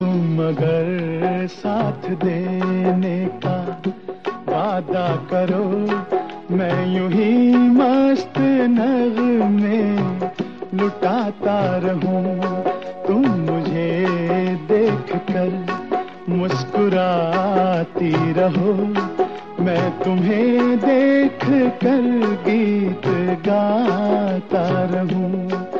soulful lyrics and melody
Romantic old song ringtone
Heart-touching love tone